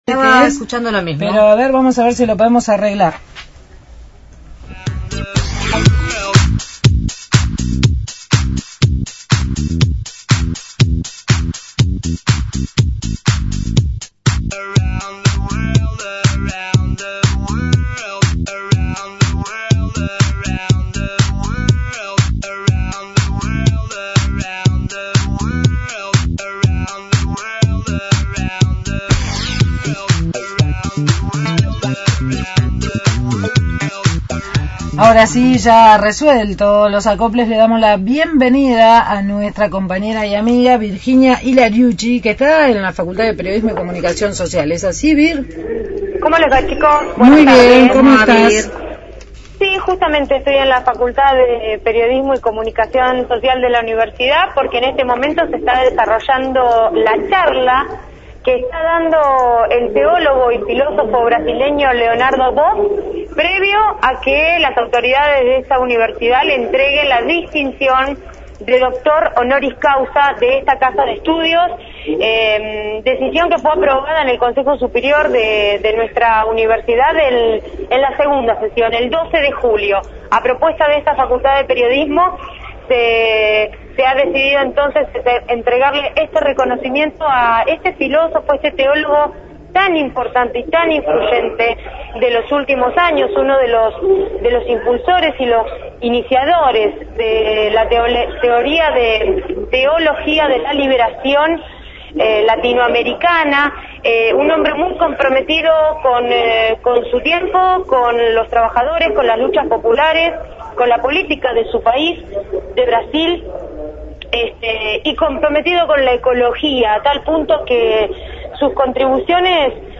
desde la Facultad de Periodismo y Comunicación Social con la Clase Magistral de Leonardo Boff y el reconocimiento como Doctor Honoris Causa por la UNLP.